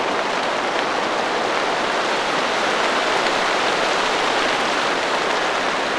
Rain2.wav